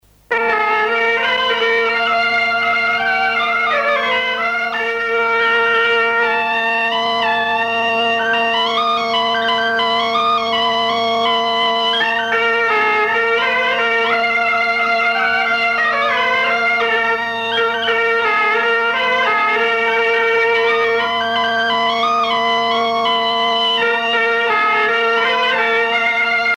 Scottish (sonnée)
danse : scottich trois pas
Pièce musicale éditée